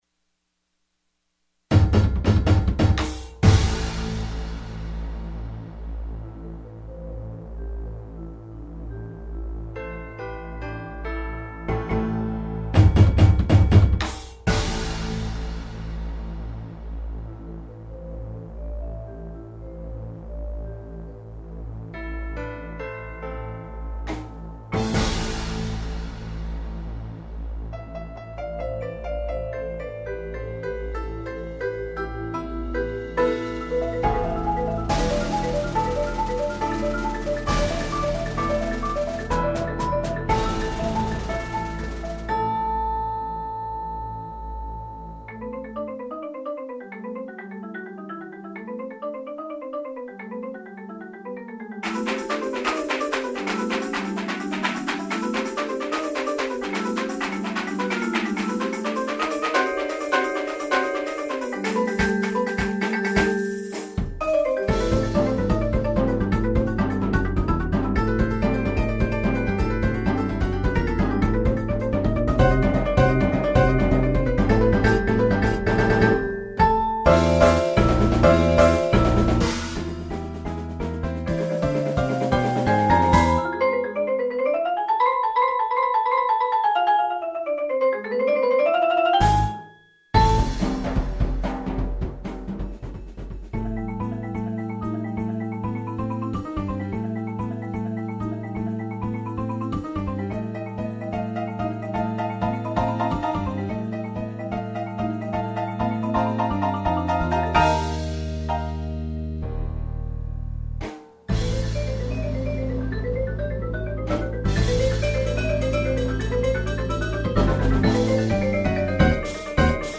• Bells
• Xylophone
• 4 Marimbas (3 - 4.3 oct, 1 - 5 oct.) Chimes
• Synth.
• Electric Bass
• Timpani
• Drum Set
• 2 Aux. Percussion